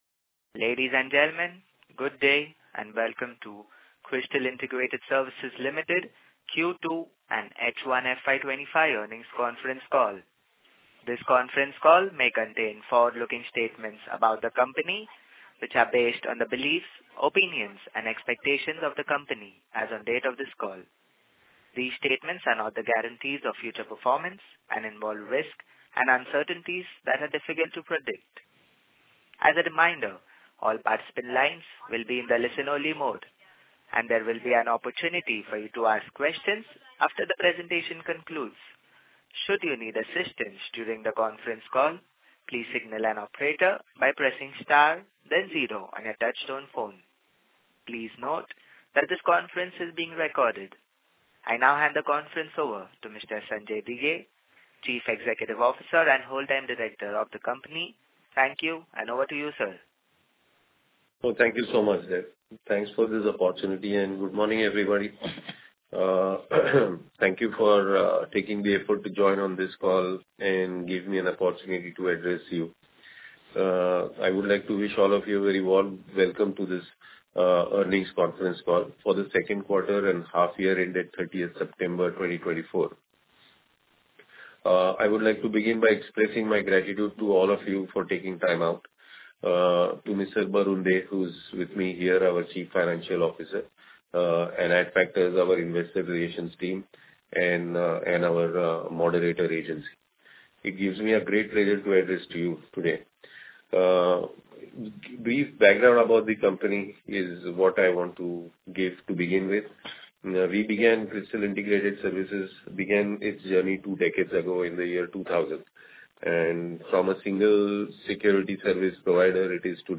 Krystal_Q2-FY25-Earnings-Call-Audio.mp3